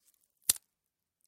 Звук отрывания одуванчика с корнем